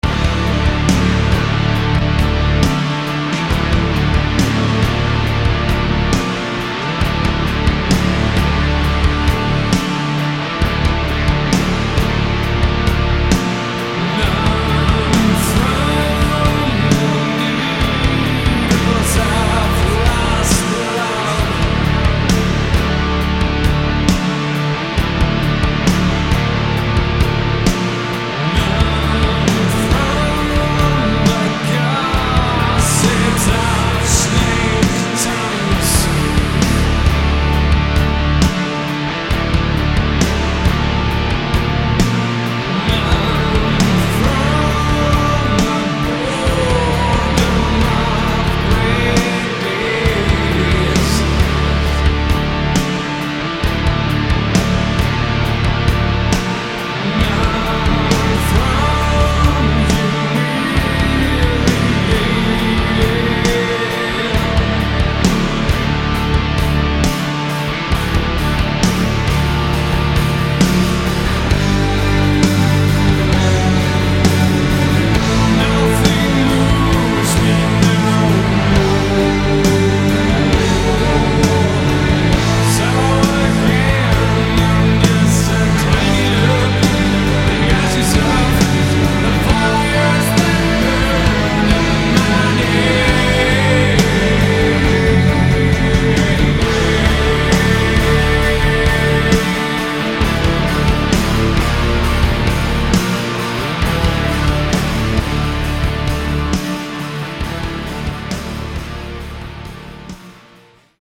The music is best described as Gothic/wave.